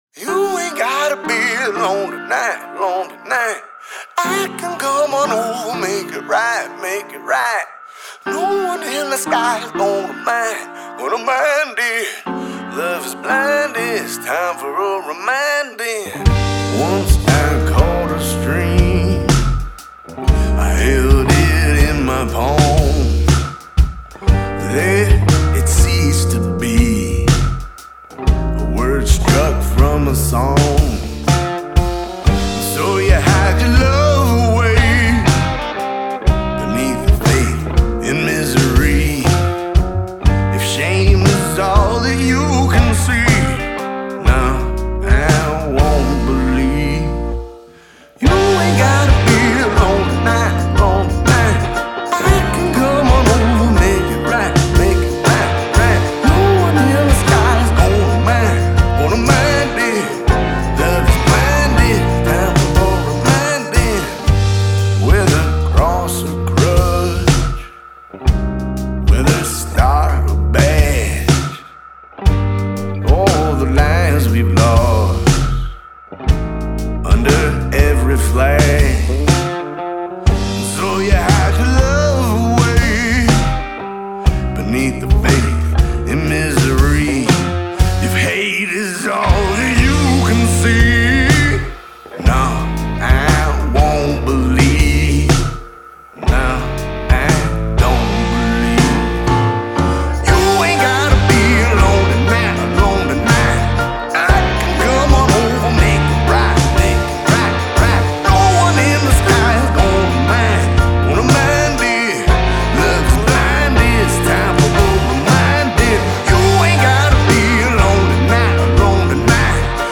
Bluesy Slow Jam LGBTQ Anthem